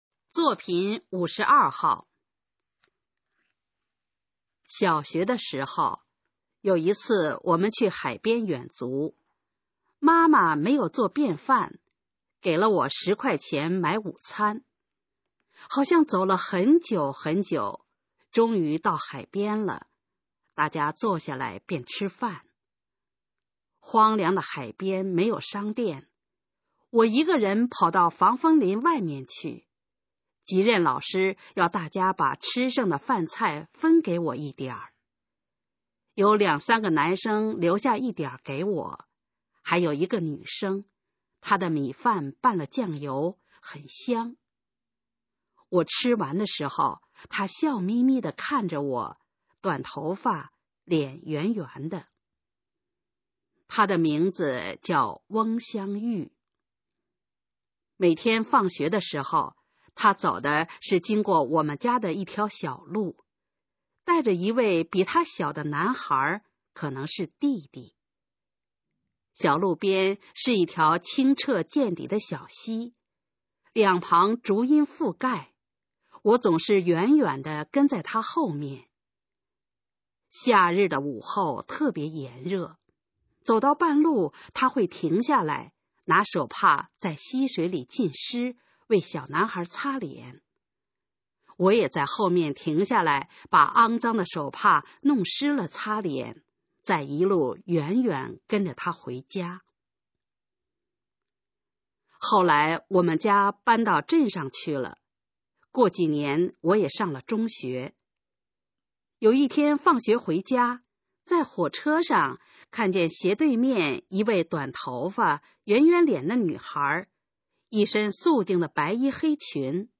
首页 视听 学说普通话 作品朗读（新大纲）
《永远的记忆》示范朗读　/ 佚名